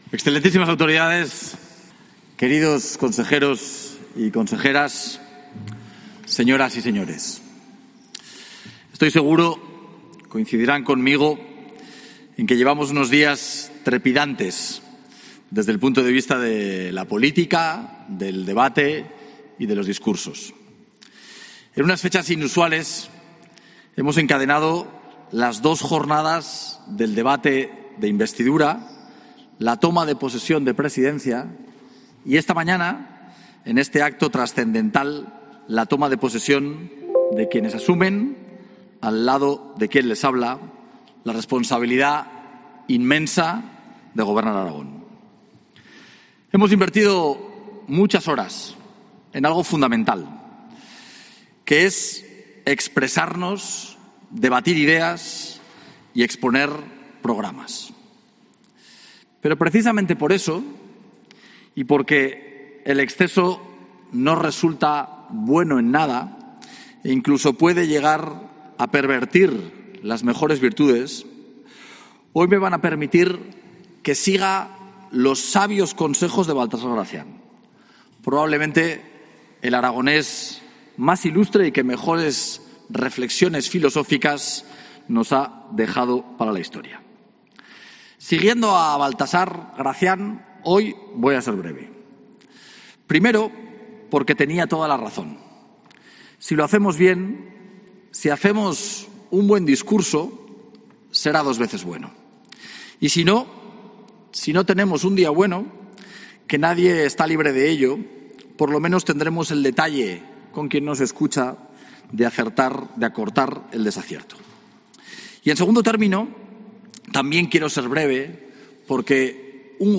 Discurso del presidente Jorge Azcón, tras la toma de posesión de los miembros del nuevo Gobierno.